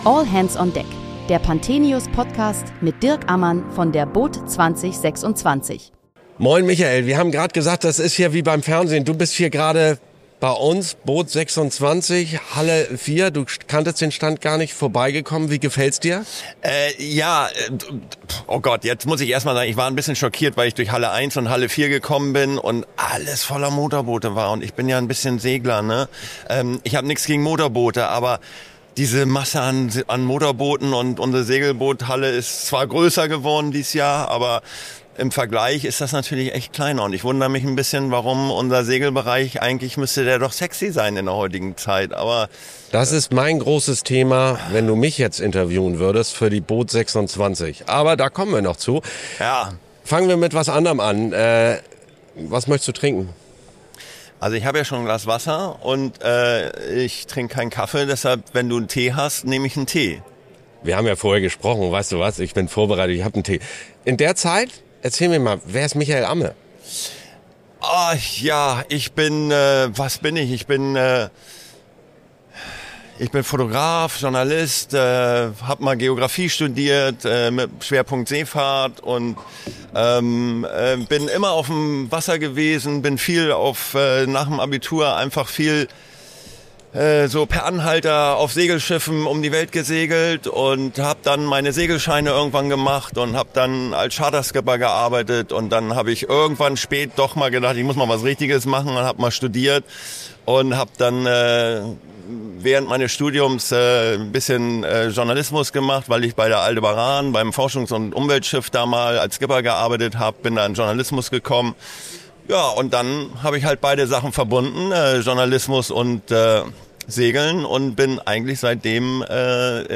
Beschreibung vor 1 Monat Willkommen zur zweiten Folge unseres Messe-Podcasts von der boot Düsseldorf 2026. Jeden Tag sprechen wir mit Gästen aus der Branche über aktuelle und kontroverse Themen rund um den Wassersport.